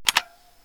key-press-3.wav